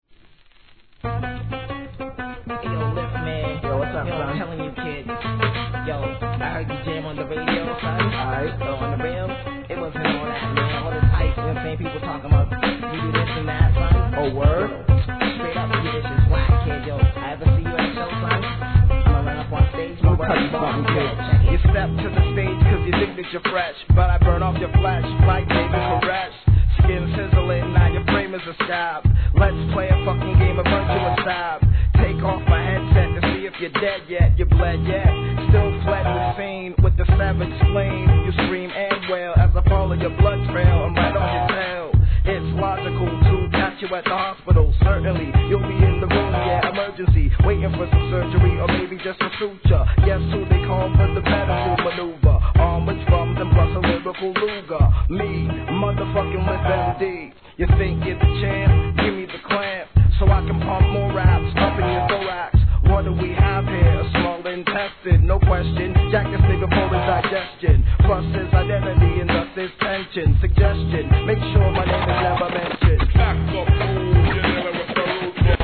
HIP HOP/R&B
癖になるLOOPにハマります!